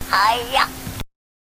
contribs)Noiseless version.